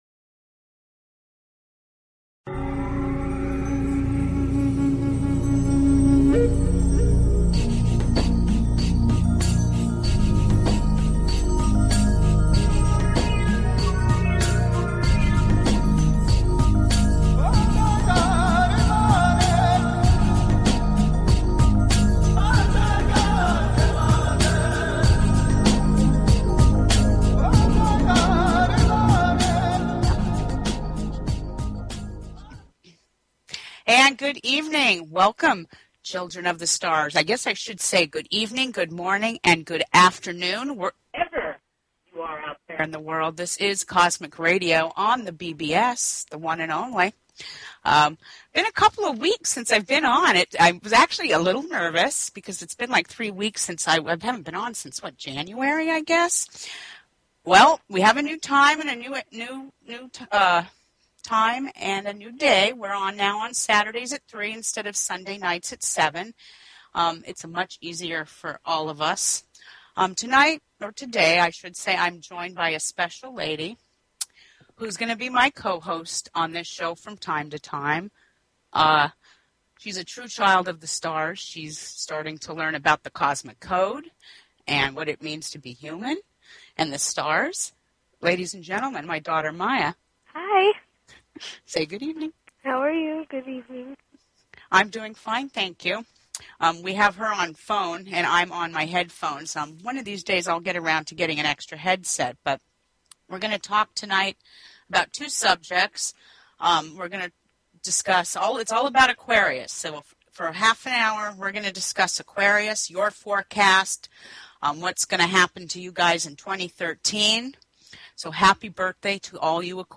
Talk Show Episode, Audio Podcast, Cosmic_Radio and Courtesy of BBS Radio on , show guests , about , categorized as